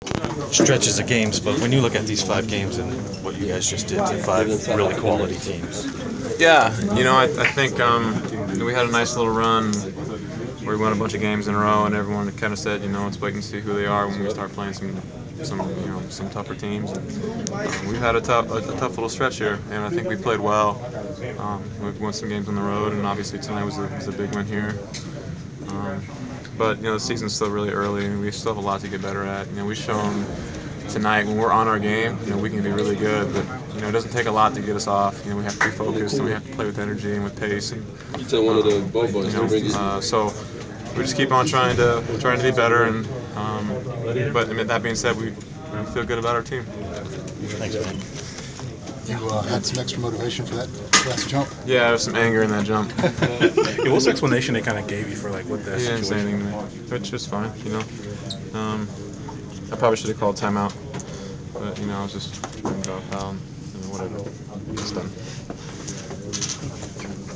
Inside the Inquirer: Postgame presser with Atlanta Hawks’ Kyle Korver (12/23/14)